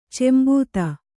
♪ cembūta